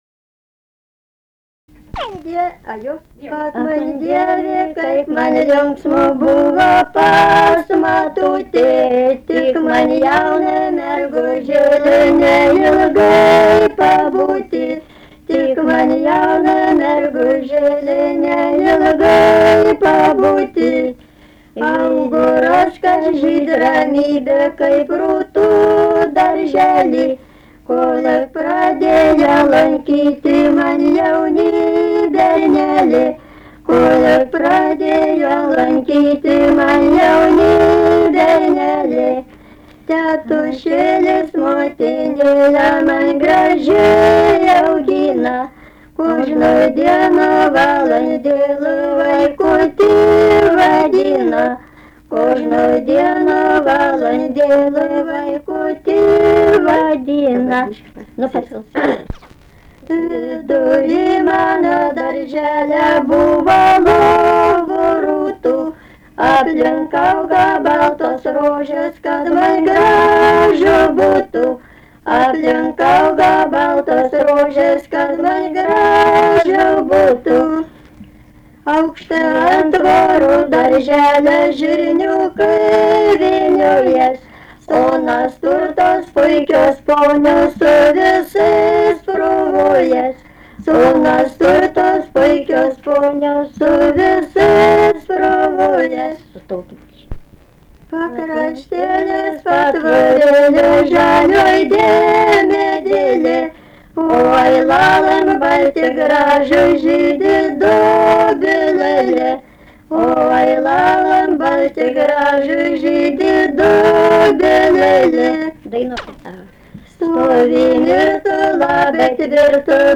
vaišių daina
Erdvinė aprėptis Mantvydai
Atlikimo pubūdis vokalinis